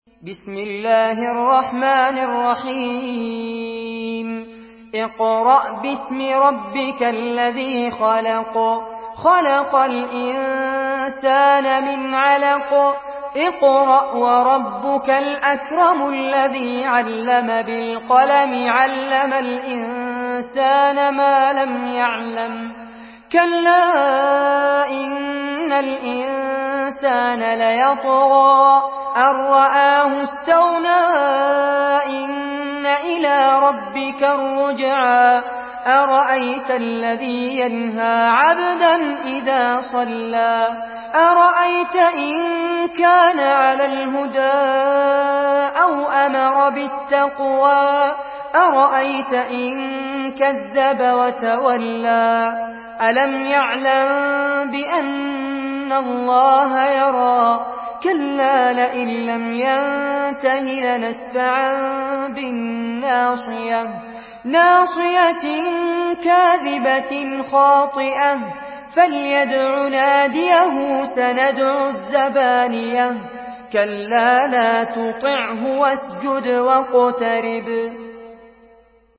Surah আল-‘আলাক্ব MP3 by Fares Abbad in Hafs An Asim narration.
Murattal Hafs An Asim